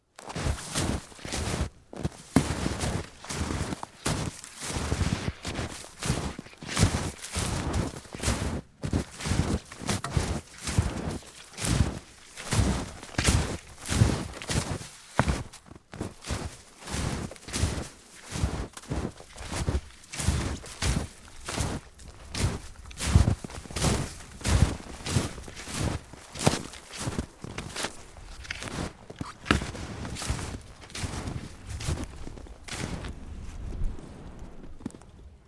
冬天" 脚步声 雪鞋 老木头2 深雪 缓慢 中速 软绵绵 蓬松 漂亮2
描述：脚步雪鞋老wood2深雪慢中速软脆蓬松nice2.flac
Tag: 脚步 雪鞋 wood2